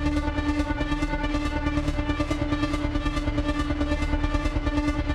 Index of /musicradar/dystopian-drone-samples/Tempo Loops/140bpm
DD_TempoDroneB_140-D.wav